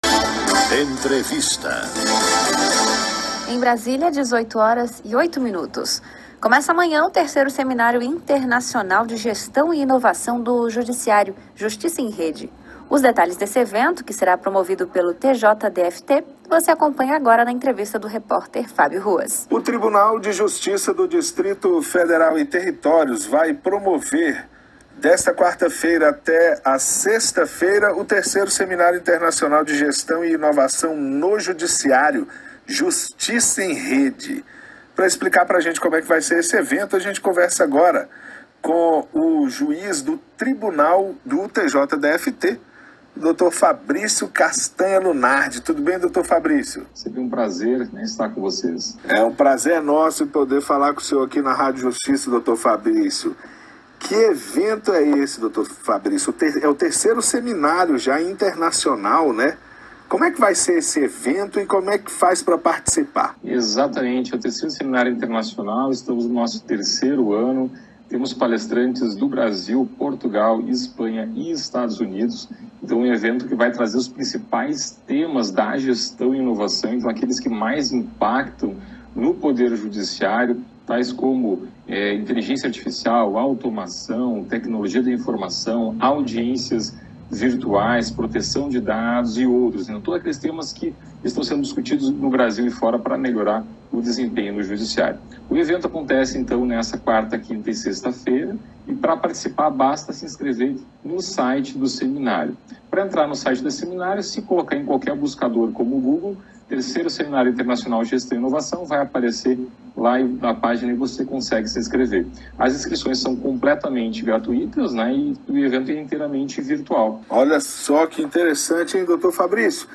Entrevista com o juiz do TJDFT Fabrício Lunardi sobre o Seminário de Gestão e Inovação do Judiciário
Tipo: Entrevista